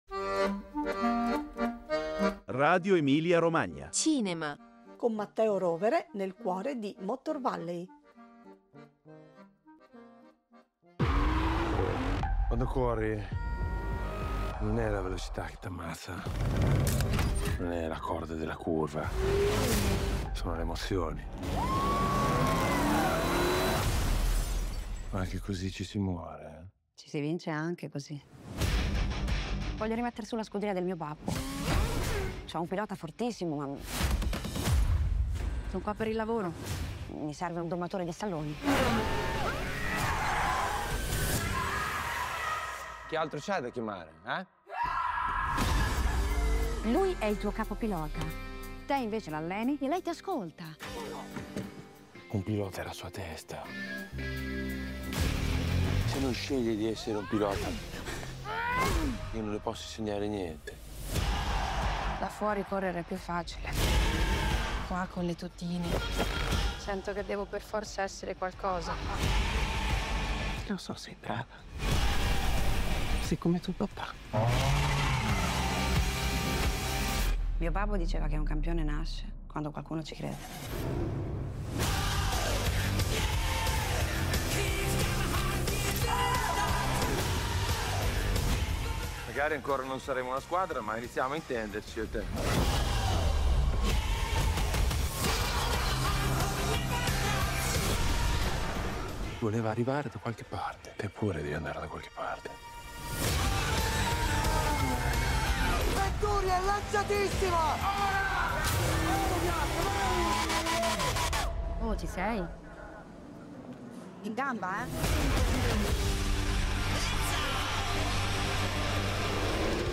Il regista e produttore ci ha parlato della serie sul campionato GT